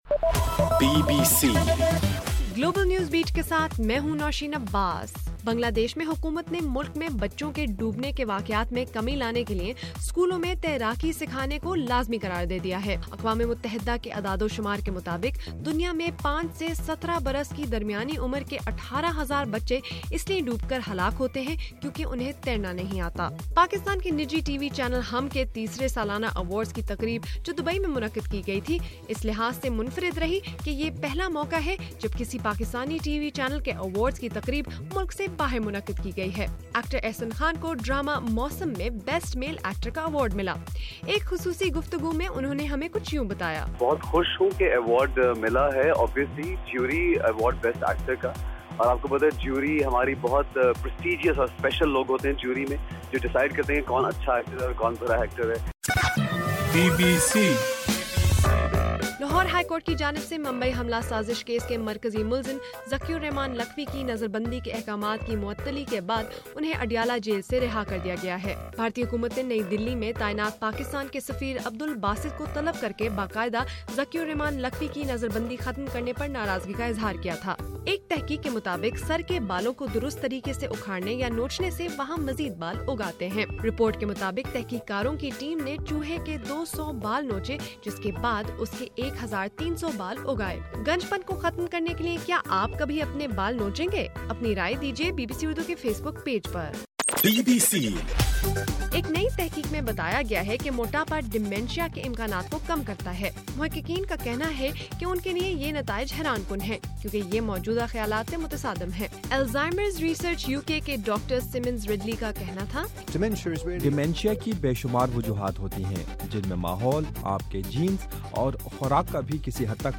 اپریل 11: صبح 1 بجے کا گلوبل نیوز بیٹ بُلیٹن